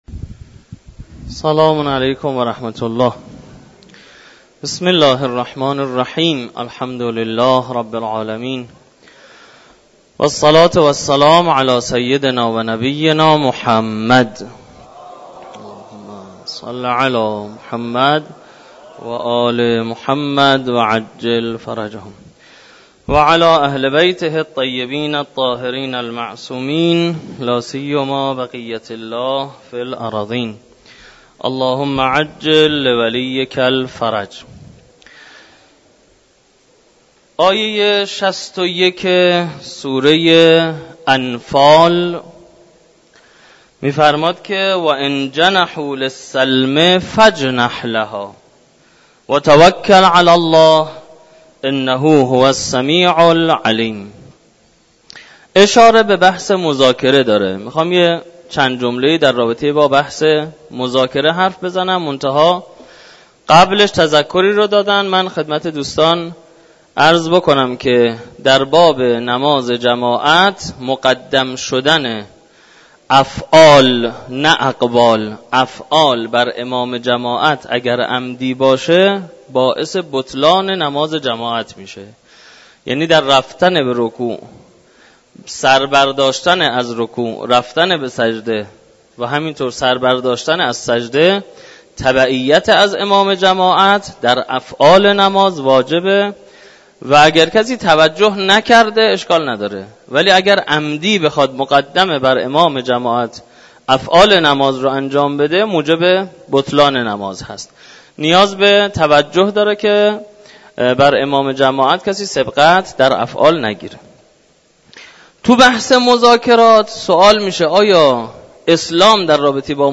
جلسه تفسیر آیه ۶۱ سوره مبارکه انفال
در مسجد دانشگاه کاشان